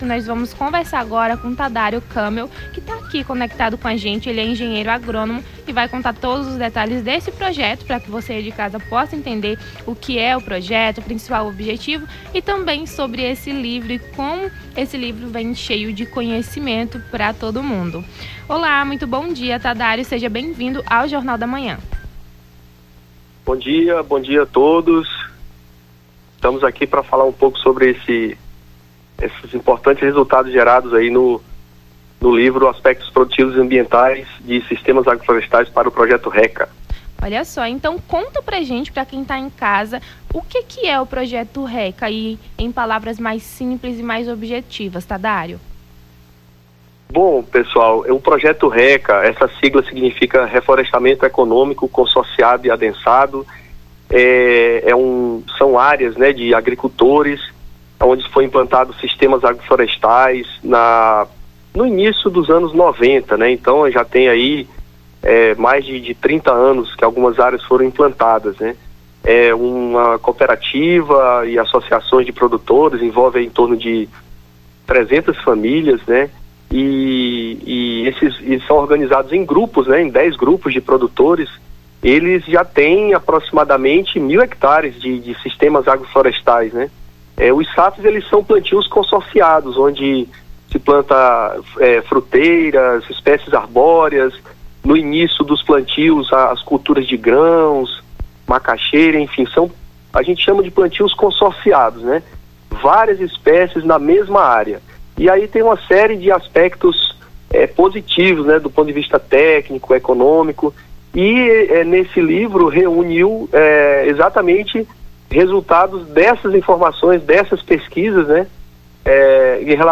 Nome do Artista - CENSURA - ENTREVISTA (LIVRO EMBRAPA) 03-07-24.mp3